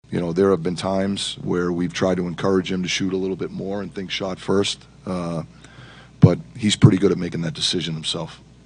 Coach Mike Sullivan says Crosby has a lot of firepower in his stick, and sometimes he had to remind him to use it.